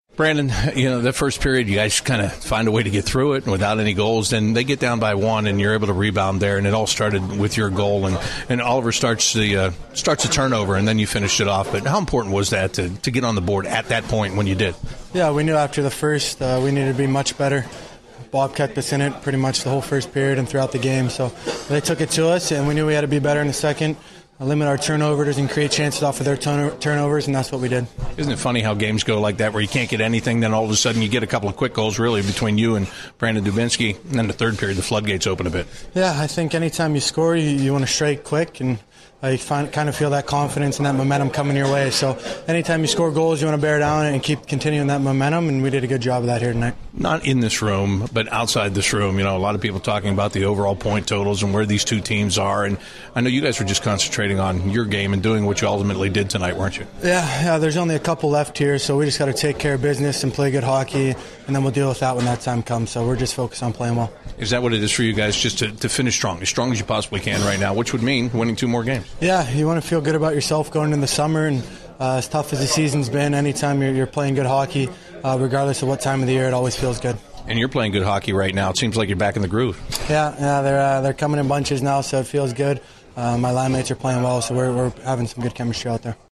Brandon Saad Post-Game 04/06/16